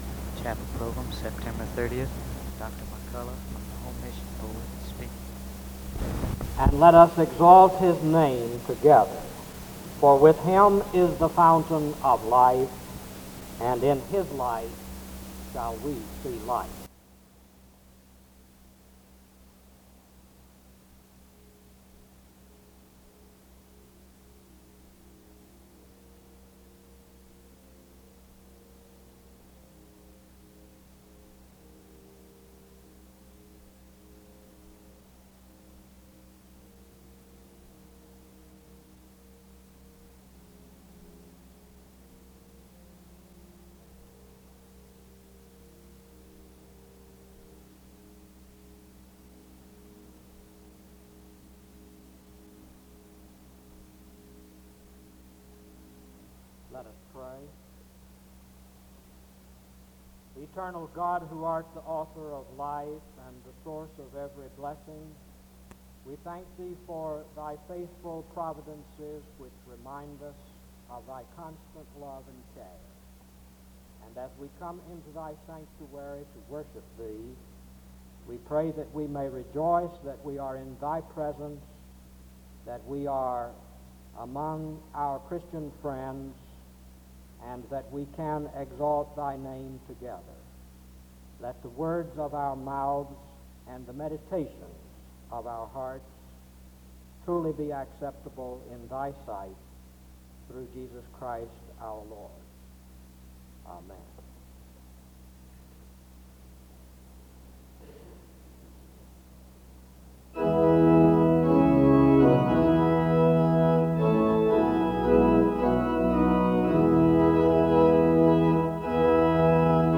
The service begins with scripture reading and music from 0:00-0:56. There is a prayer from 0:57-1:44. Music plays from 1:50-5:21. A responsive reading takes place from 5:27-7:26. There is an introduction to the speaker from 7:30-8:28.